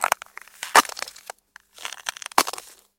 Careful footsteps on a frozen surface with crunching frost and subtle cracking
walking-on-ice.mp3